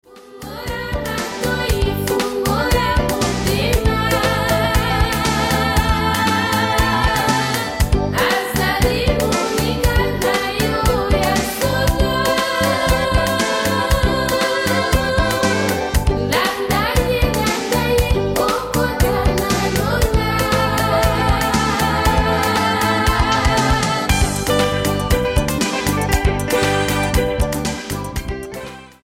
STYLE: World